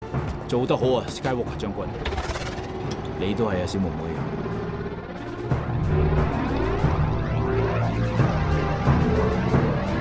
The situation this time around is a high-ranking clone soldier (Rex?) speaking to Anakin and Ahsoka after the first battle of the movie.